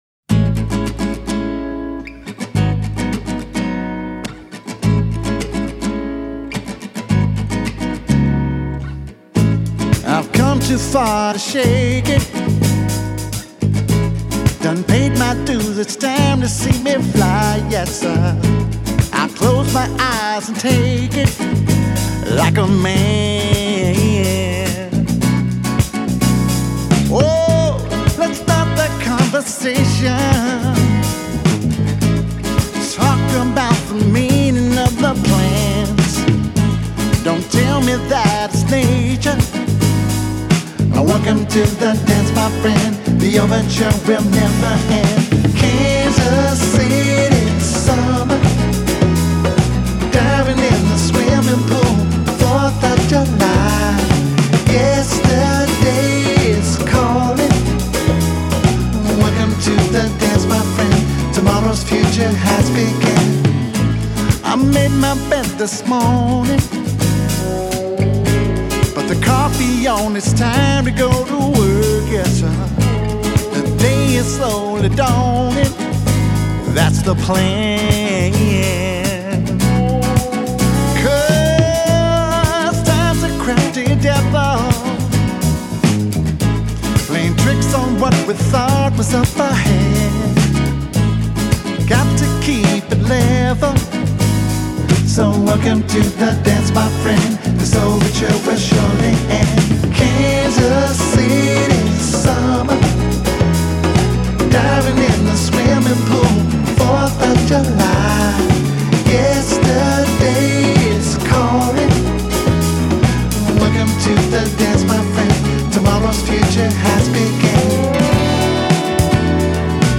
Country soul, cool et nostalgique
Une musique chaleureuse, radieuse et un brin mélancolique.